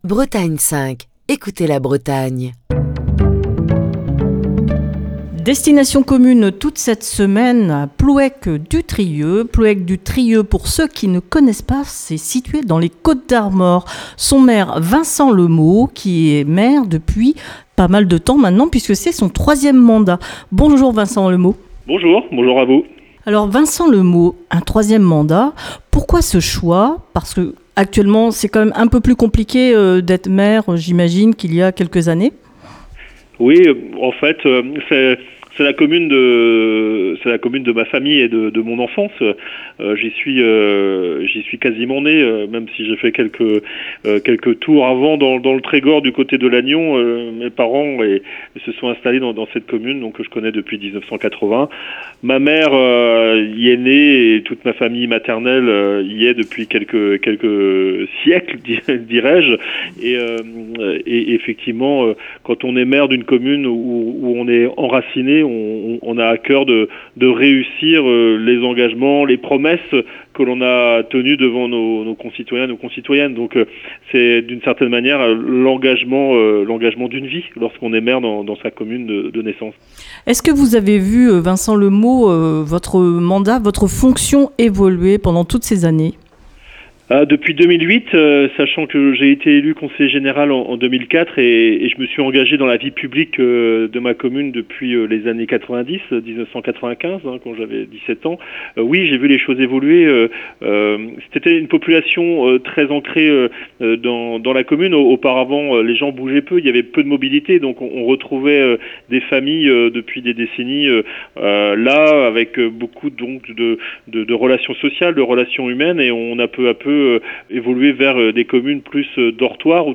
Émission du 18 mars 2024. Cette semaine, Destination Commune met le cap sur les Côtes d'Armor, et vous emmène à Plouëc-du-Trieux.